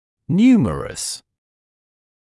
[‘njuːmərəs][‘нйумэрэс]многочисленный